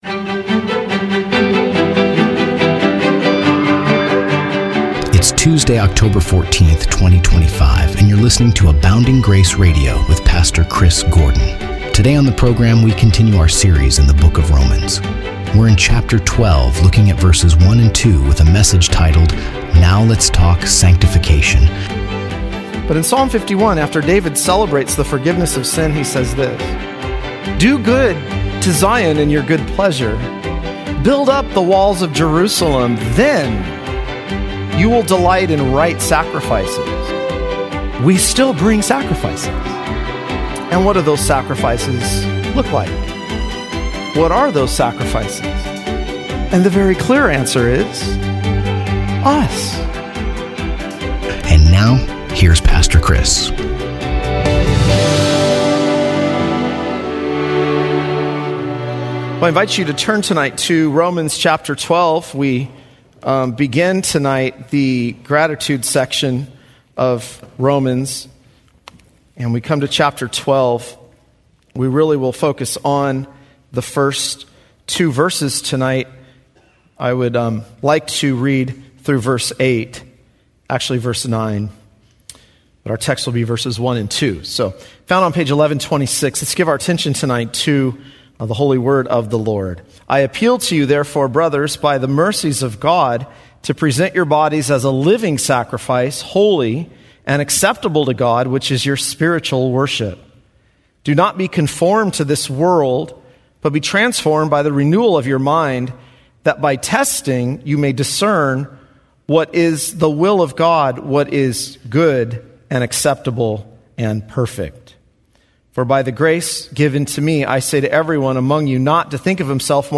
Radio Broadcasts